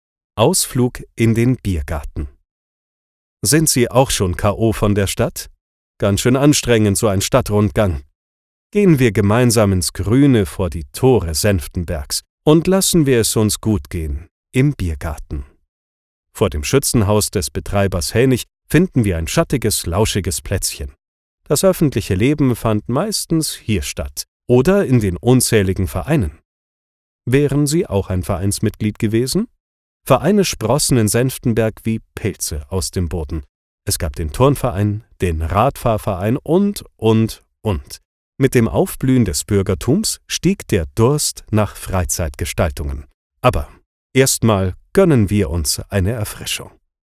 Kommerziell, Natürlich, Zuverlässig, Freundlich, Corporate
Audioguide